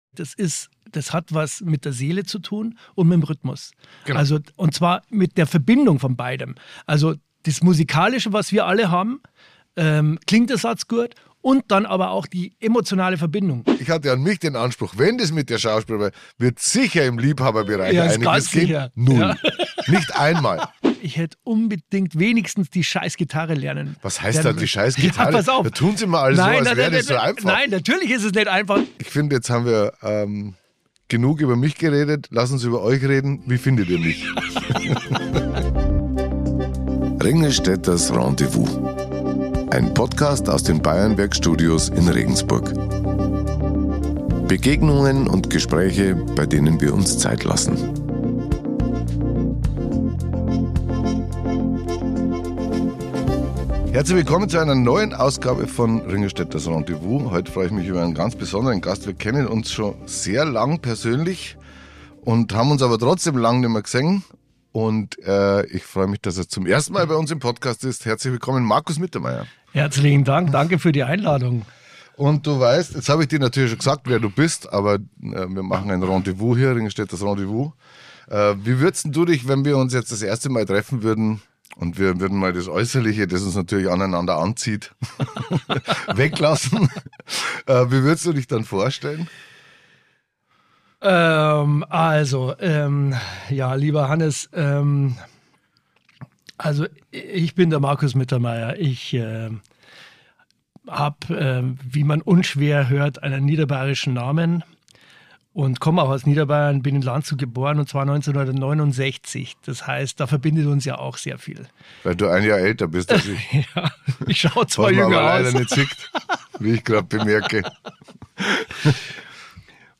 Beschreibung vor 1 Monat In dieser Folge spreche ich mit dem Schauspieler Markus Mittermeier. Wir blicken auf gemeinsame Stationen in Landshut und Regensburg, auf erste Schritte als Komparsen und auf Umwege, die uns beide geprägt haben. Markus erzählt von seinem Werdegang, vom vergleichsweise spätem Karrierestart, vom Wesen des Schauspielberufs zwischen Emotionalität und Musikalität – und von seiner Leidenschaft fürs Gitarrespielen.